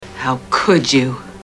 Tags: Kathy Bates Kathy Bates quote Kathy Bates clips Misery dolores claiborne